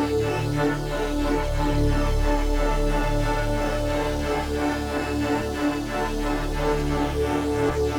Index of /musicradar/dystopian-drone-samples/Tempo Loops/90bpm
DD_TempoDroneD_90-D.wav